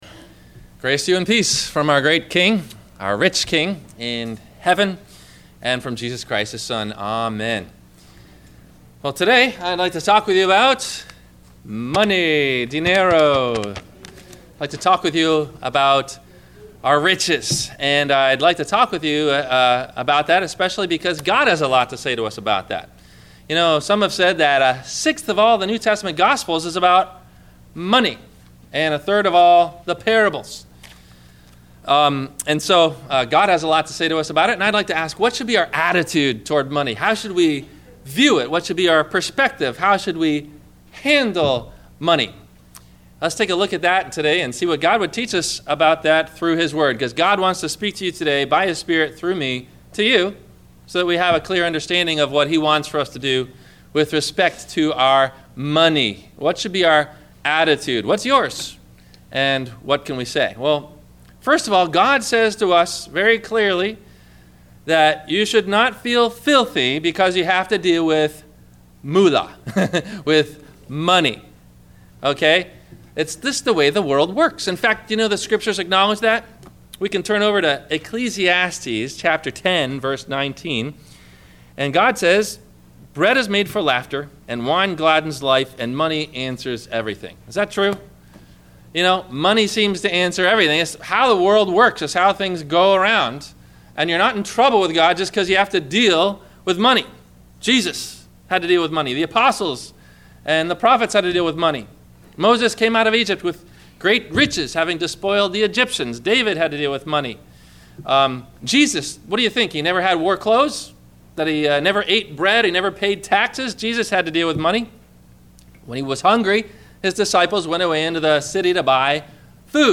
- Sermon - November 15 2015 - Christ Lutheran Cape Canaveral